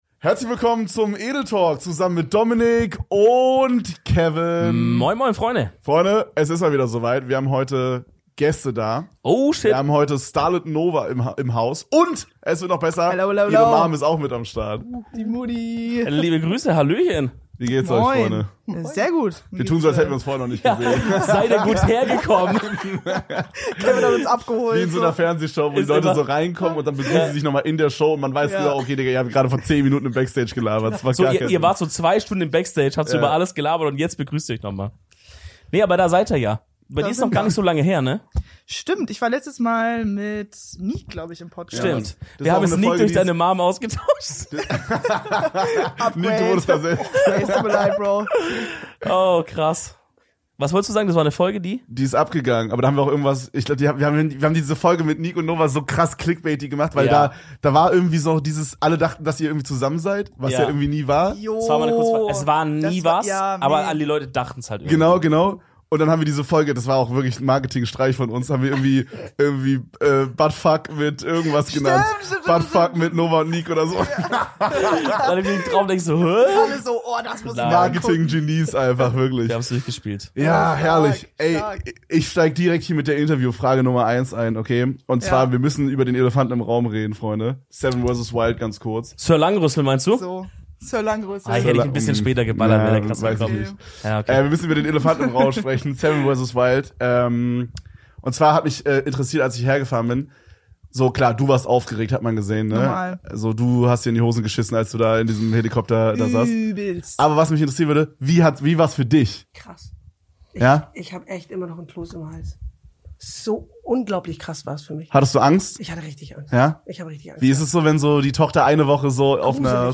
Gästezeit.